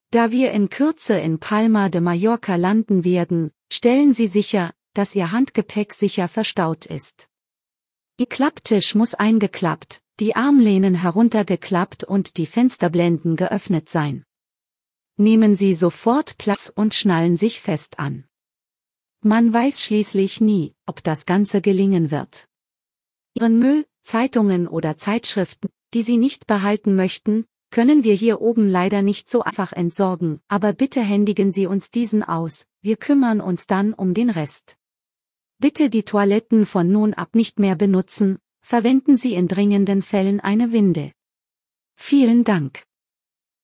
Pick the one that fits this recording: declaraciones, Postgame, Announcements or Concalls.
Announcements